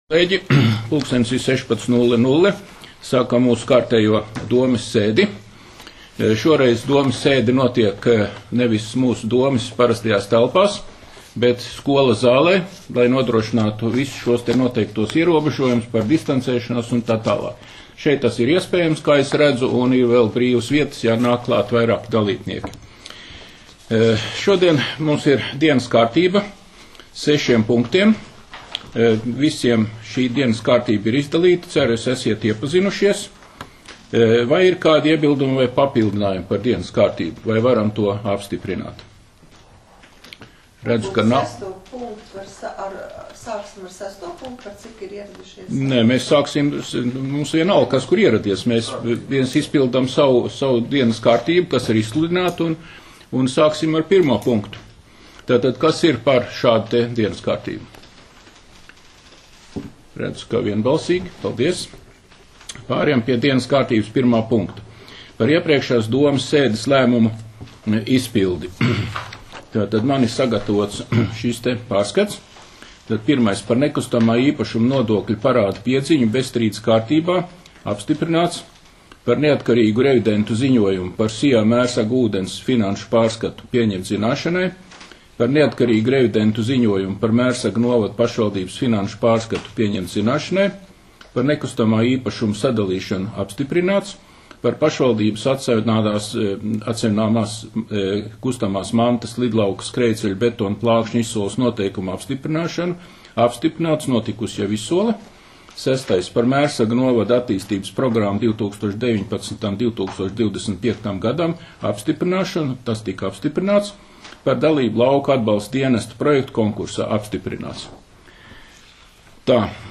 Mērsraga novada domes sēde 16.06.2020.